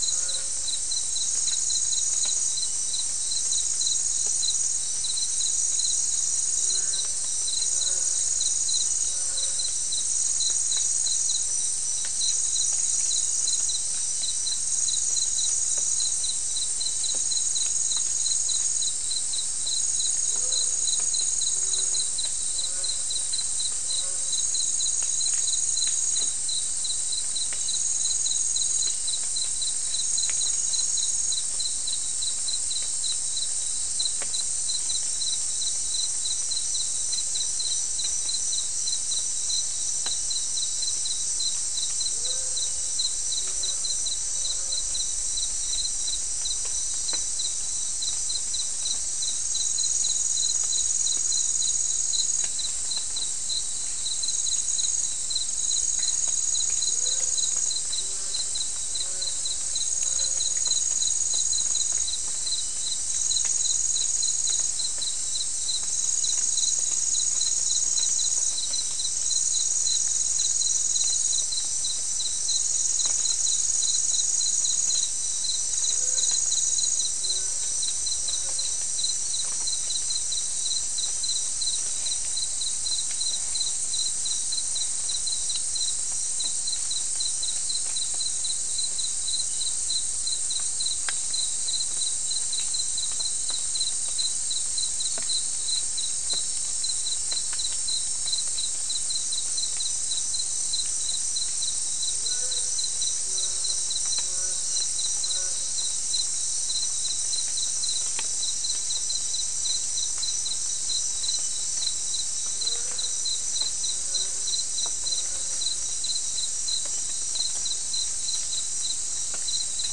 Soundscape Recording Location: South America: Guyana: Mill Site: 3
Recorder: SM3